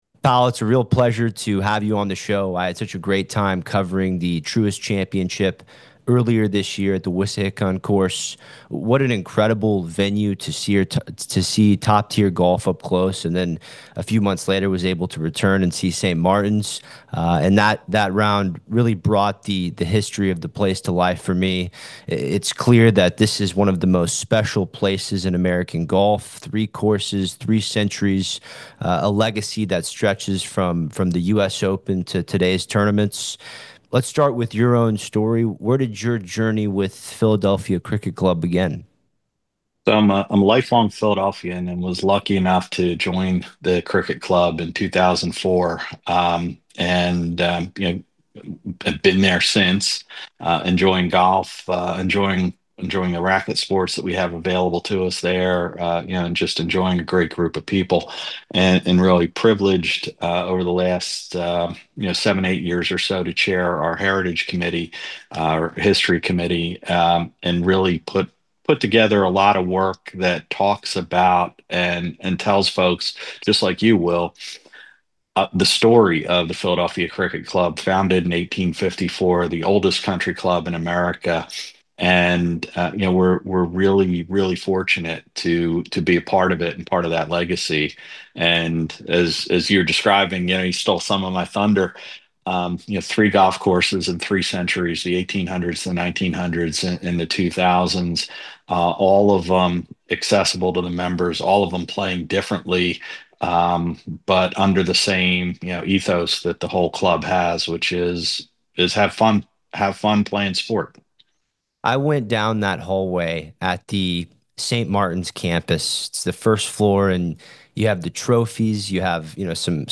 The conversation also dives into the influence of legendary architects like A.W. Tillinghast and George Thomas, the vision of the Houston family, and the enduring charm of the St. Martins Course.